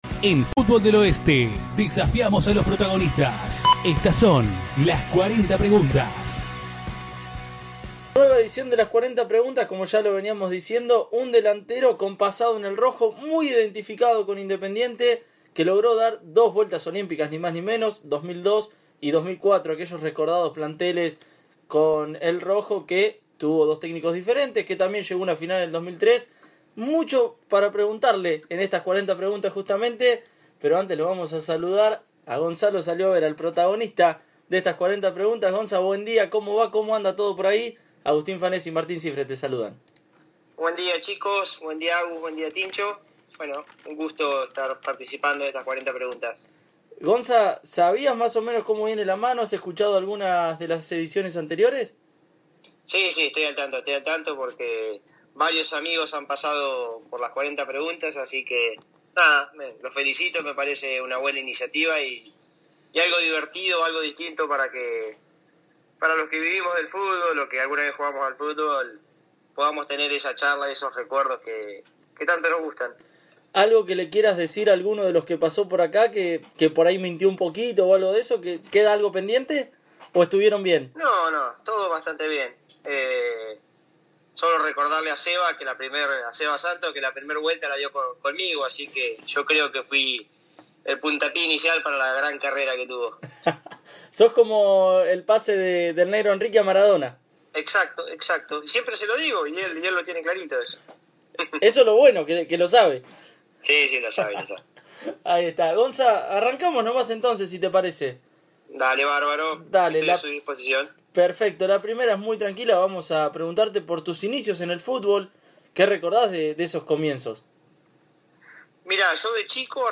En este mano a mano imperdible, el rivadaviense respondió absolutamente a todo: ¿Qué recuerda de su debut en primera división?